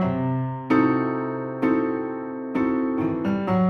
Index of /musicradar/gangster-sting-samples/130bpm Loops
GS_Piano_130-C2.wav